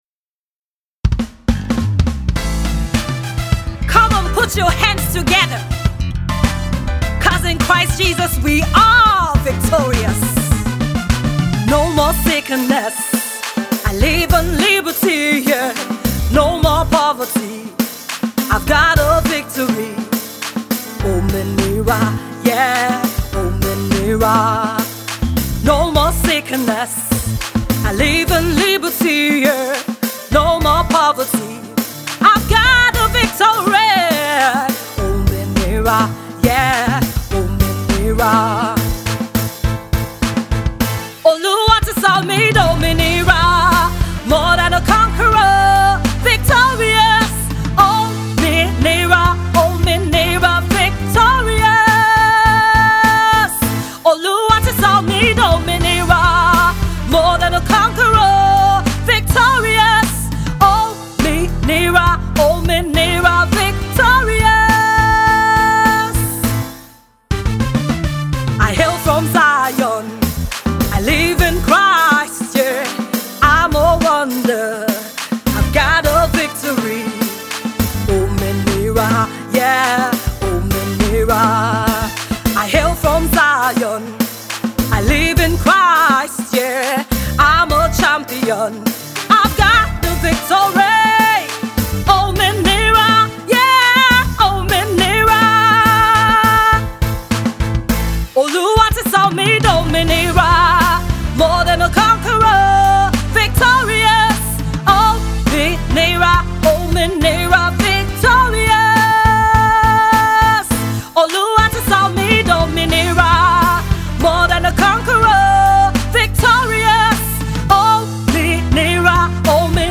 It’s a mixture of hip pop and afro blend.